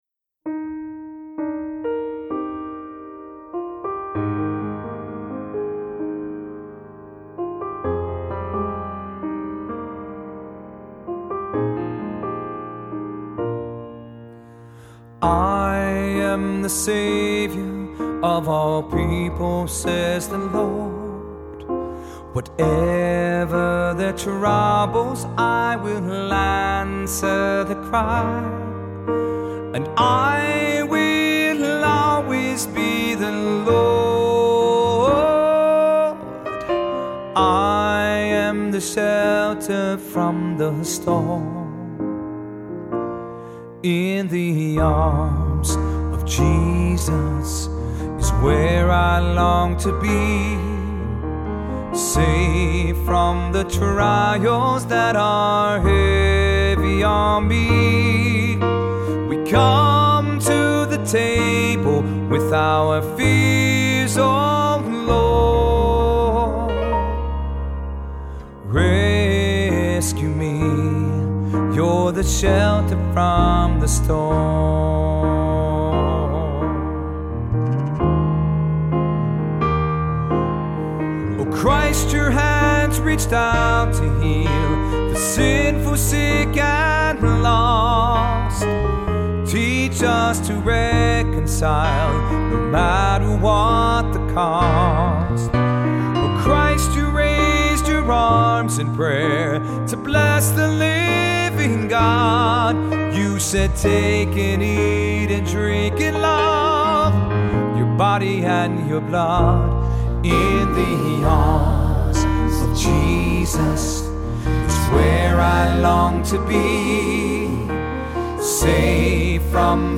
Voicing: "SAT, Cantor, Assembly"